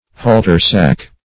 Search Result for " halter-sack" : The Collaborative International Dictionary of English v.0.48: Halter-sack \Hal"ter-sack`\ (h[add]l"t[~e]r*s[a^]k`), n. A term of reproach, implying that one is fit to be hanged.
halter-sack.mp3